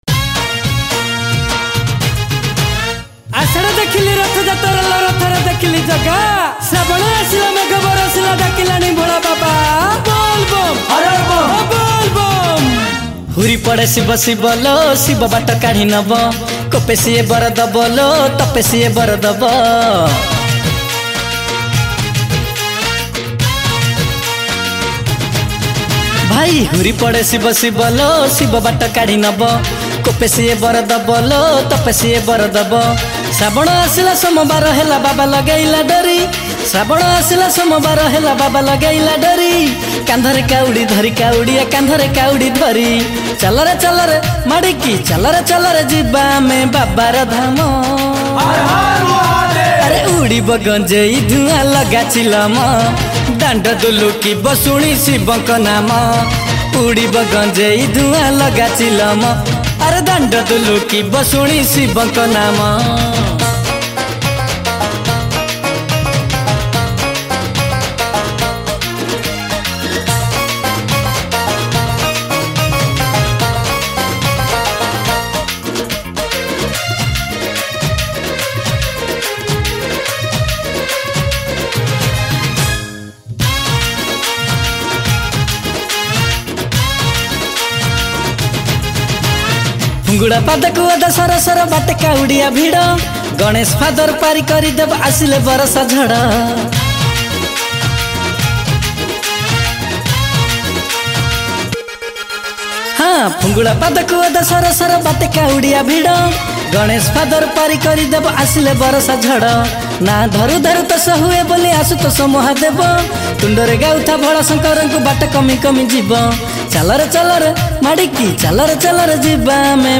Bolbum Special Song Songs Download